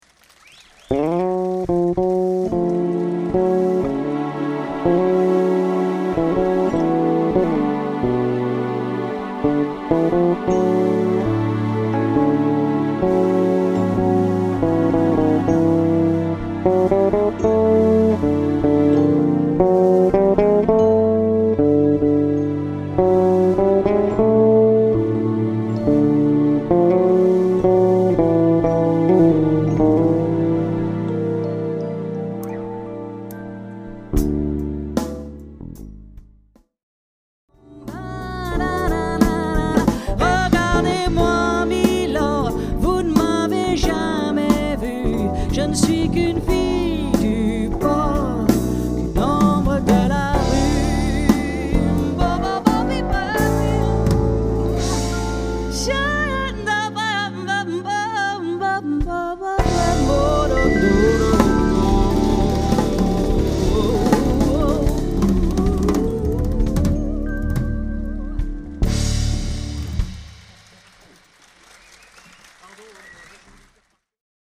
Bass / Double Bass
Drums
Keyboards
Vocal